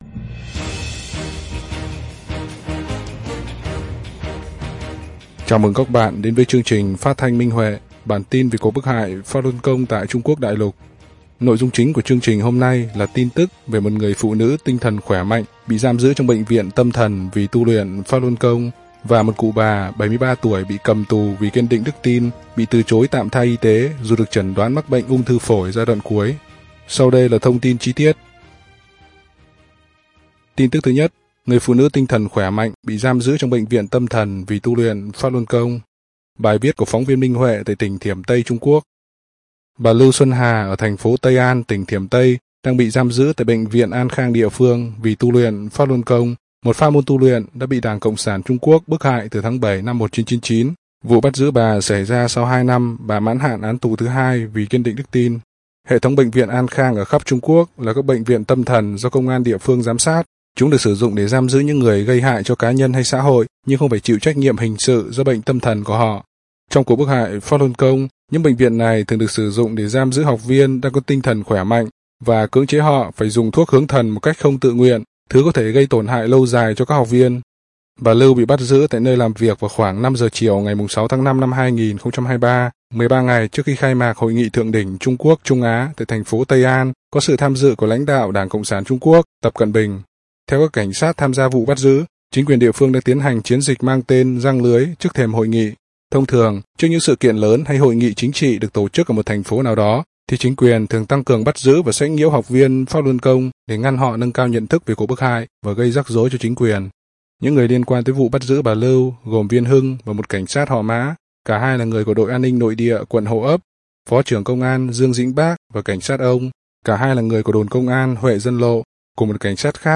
Chương trình phát thanh số 21: Tin tức Pháp Luân Đại Pháp tại Đại Lục – Ngày 6/7/2023